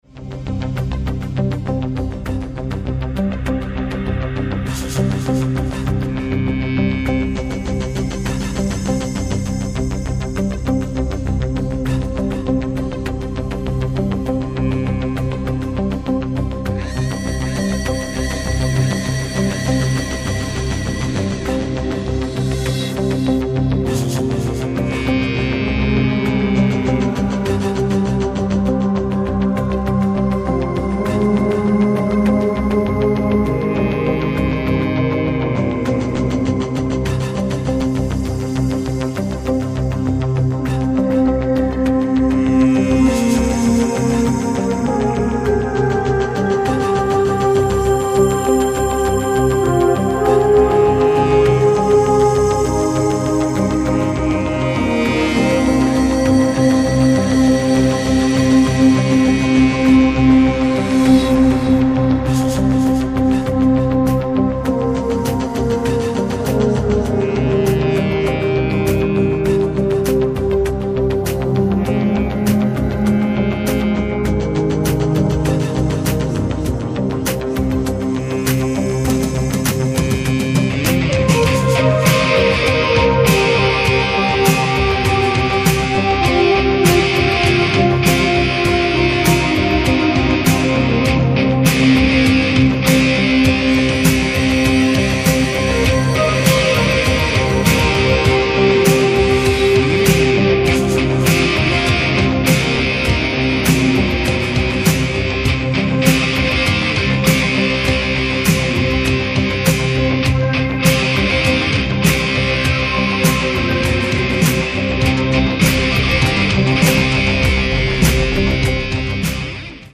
20.02.2005 w klubie DELTA